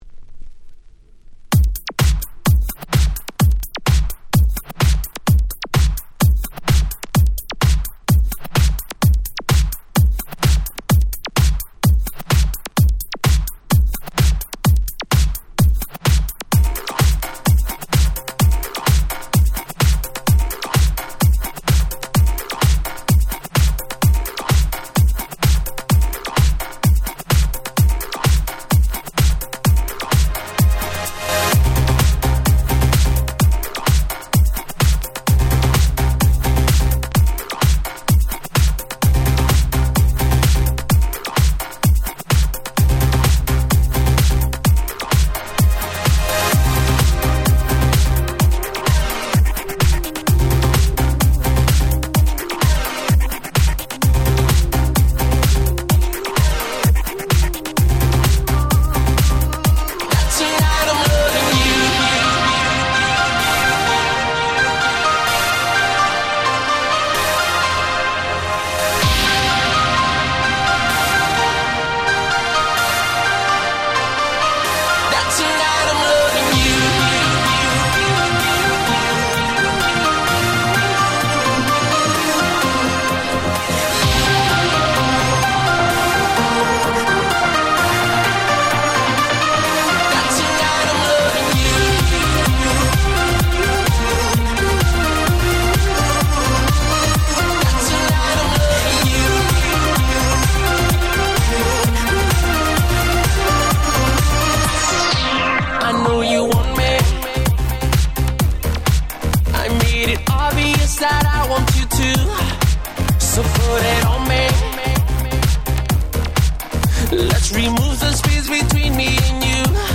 10' Super Hit R&B / Pops / Latin !!